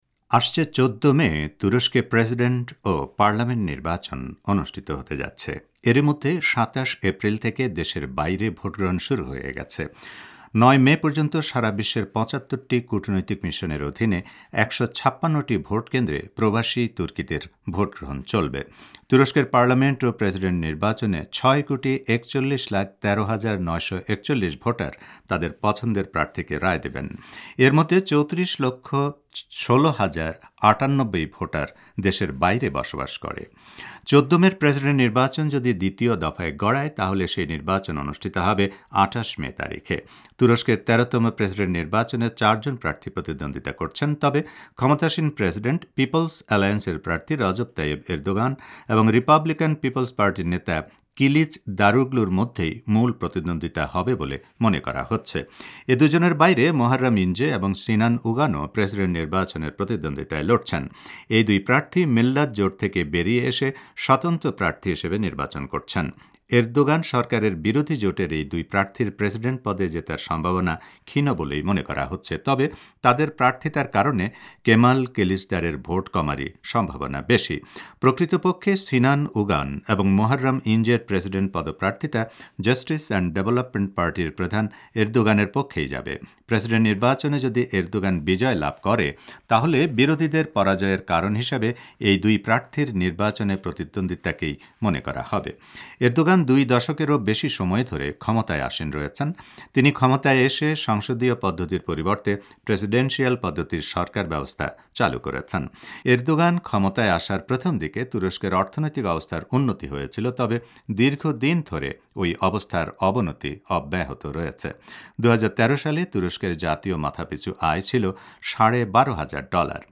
তুরস্কে প্রেসিডেন্ট ও পার্লামেন্ট নির্বাচন: বিশ্লেষকের অভিমত